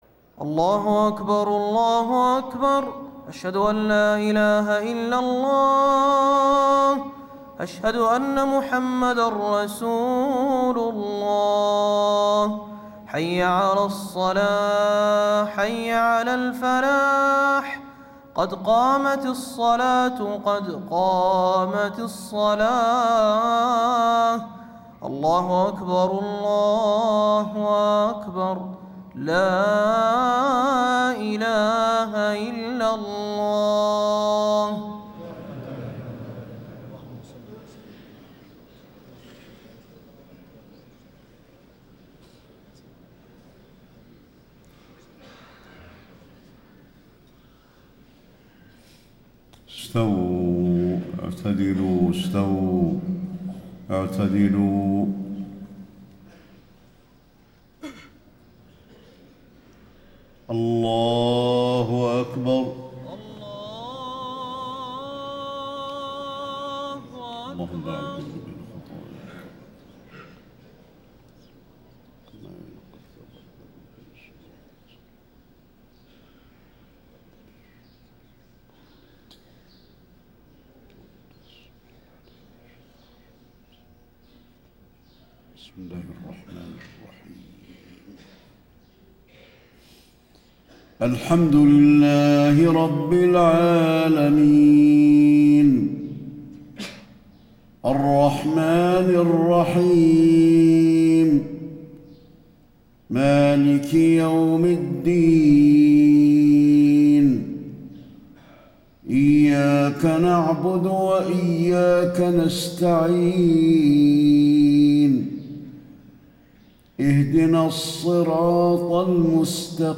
صلاة الفجر 8-4-1435هـ من سورة الفرقان > 1435 🕌 > الفروض - تلاوات الحرمين